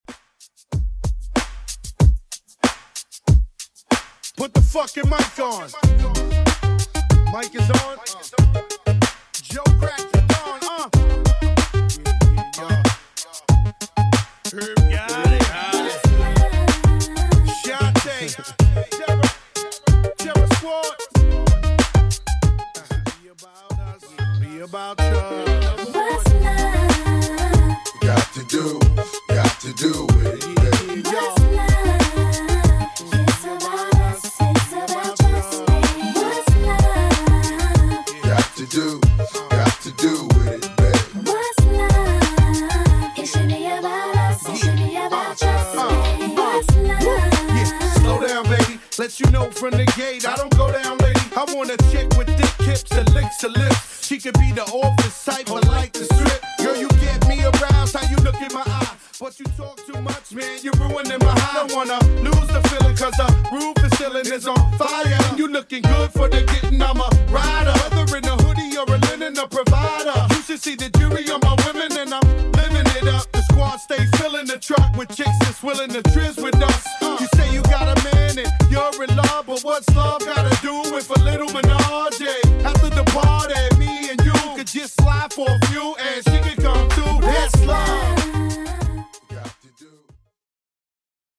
Genre: 60's